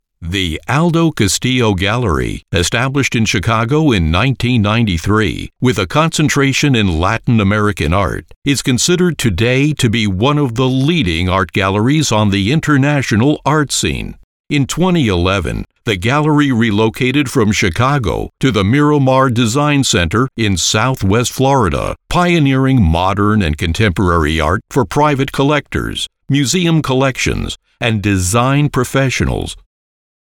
Comercial, Profundo, Natural, Llamativo, Amable
Audioguía